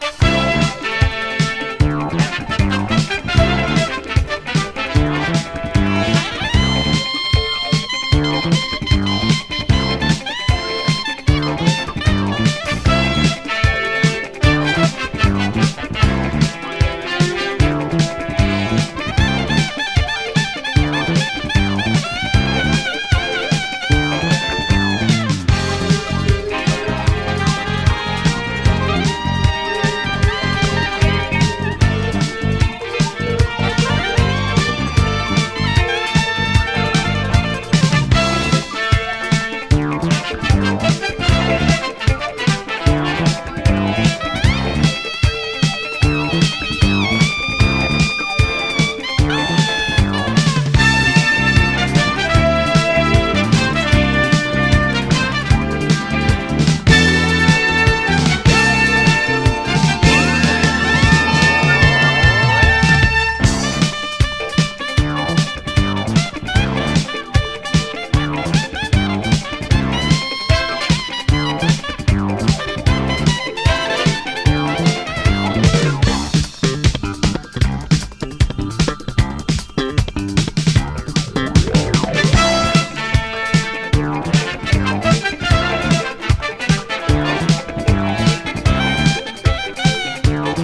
TEEN POP
ロッキンなアイドル・ティーン・ポップ！
溌剌としたポップ・チューンがギッシリ！